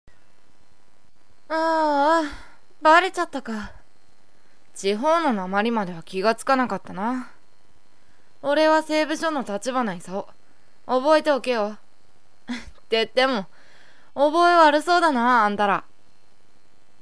かなりの女顔で、声もボーイソプラノ系と、非常に高く、
Alike内の台詞